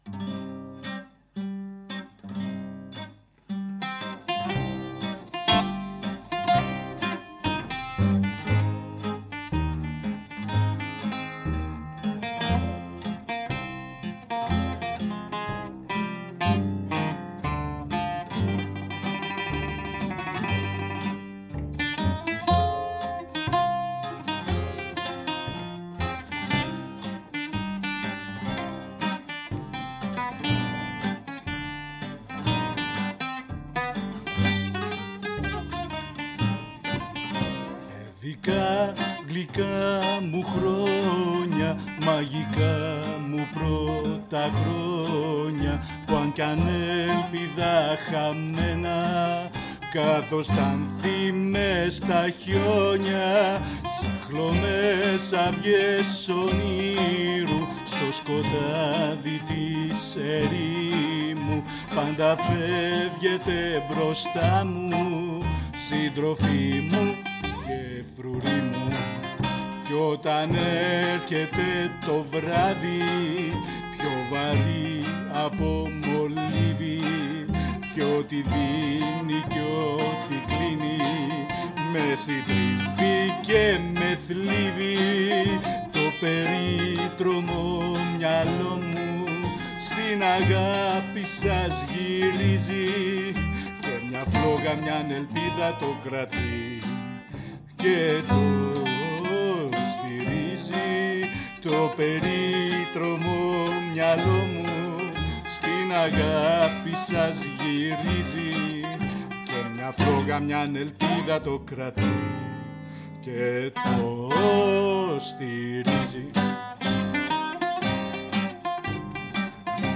zang, gitaar
bouzouki , knopaccordeon , baglamas , zang